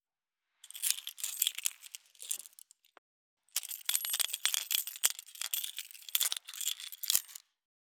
166.鍵【無料効果音】